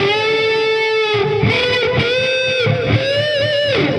Index of /musicradar/80s-heat-samples/120bpm